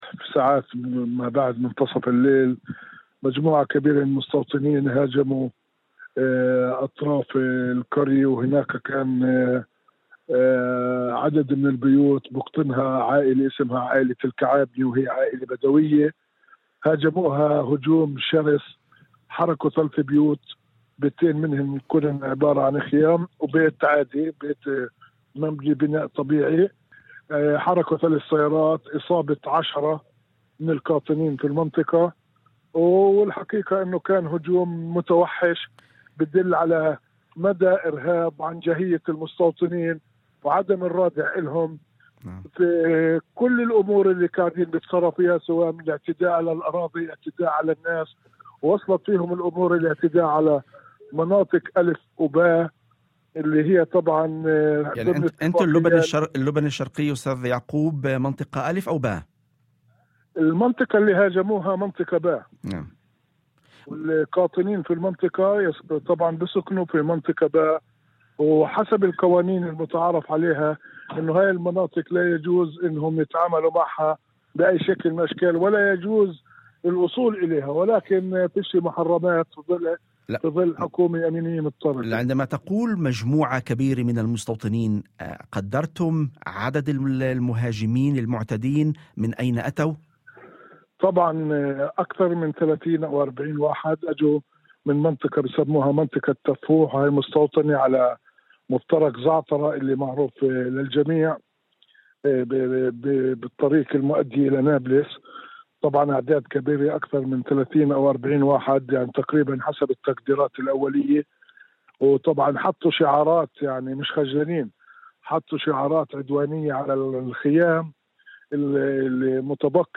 في مداخلة هاتفية ضمن برنامج "يوم جديد" على إذاعة الشمس